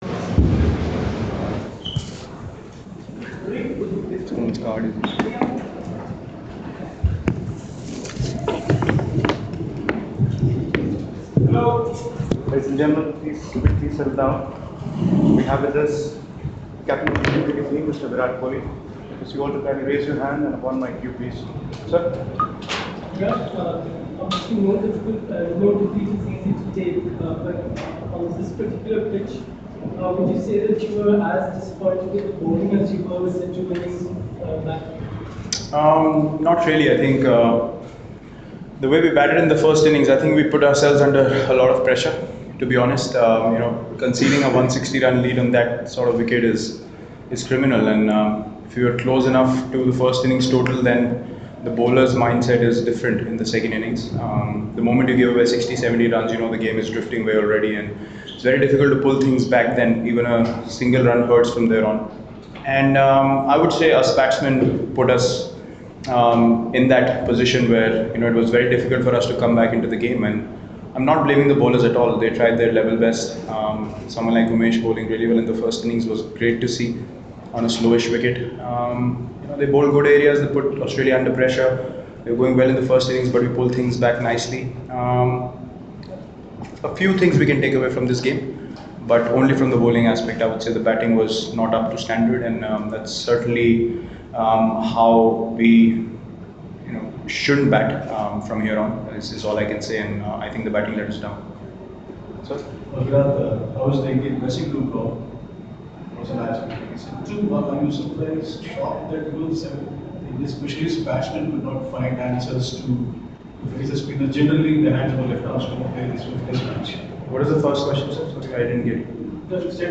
LISTEN: Skipper Virat Kohli speaks after India's defeat in Pune